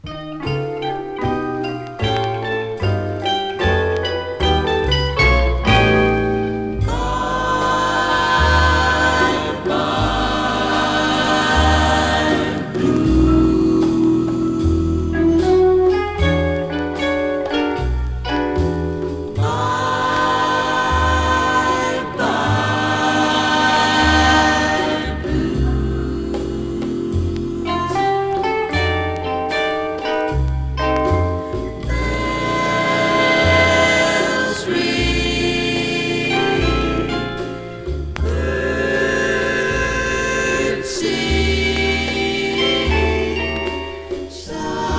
男女混声ヴォーカルグループ
Jazz, Pop, Vocal, Easy Listening　USA　12inchレコード　33rpm　Stereo